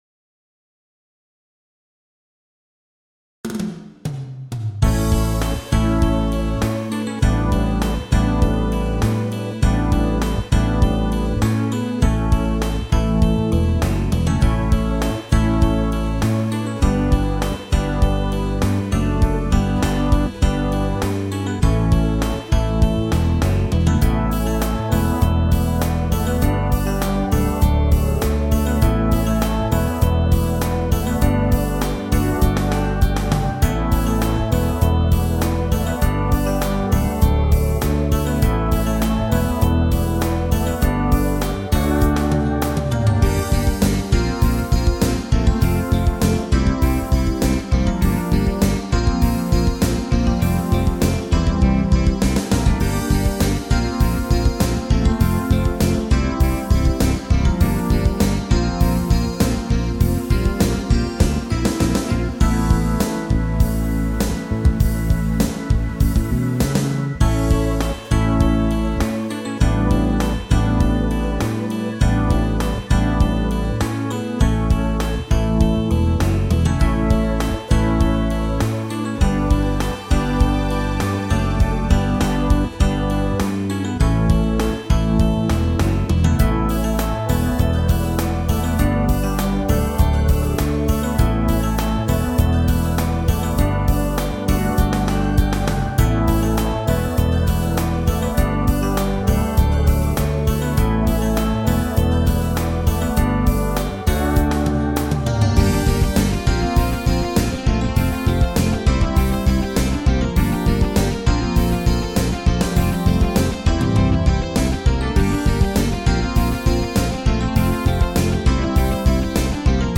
Yamaha Motif XF